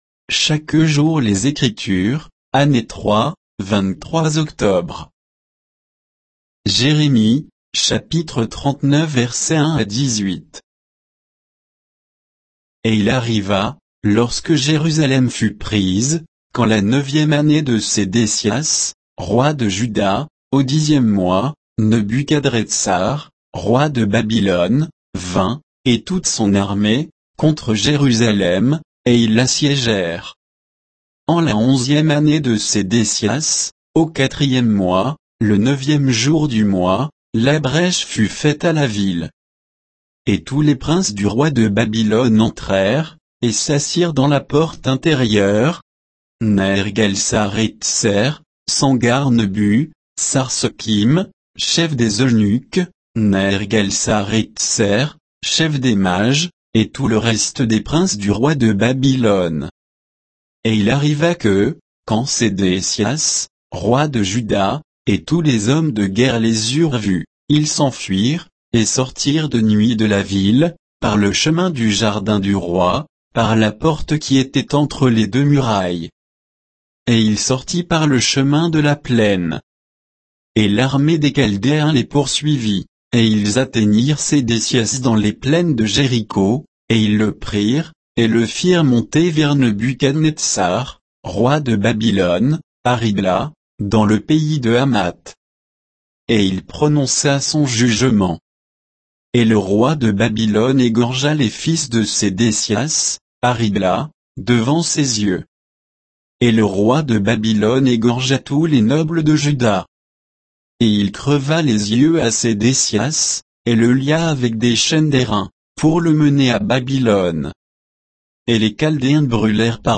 Méditation quoditienne de Chaque jour les Écritures sur Jérémie 39